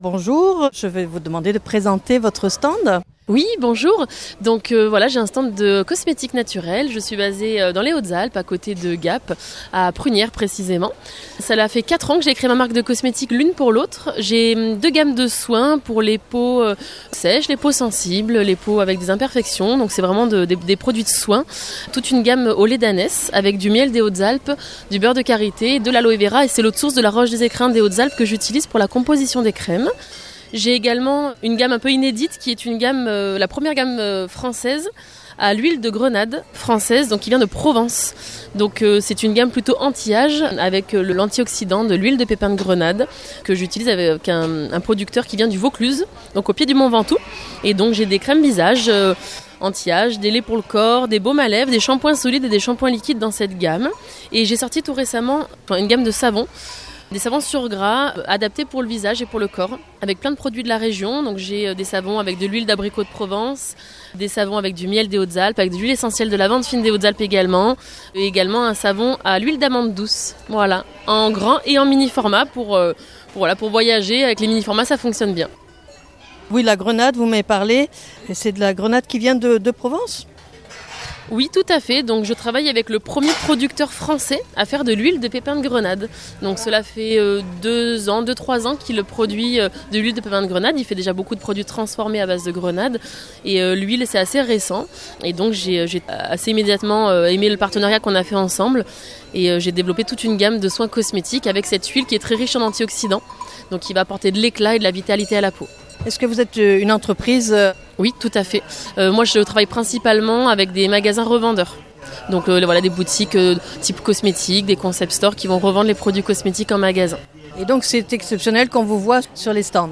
Lune à l'autre sur le Marché du Terroir à Digne les bains Une gamme de produits cosmétiques au lait d'ânesse bio et miel des Alpes. et une gamme de produits cosmétiques à la grenade de Provence bio.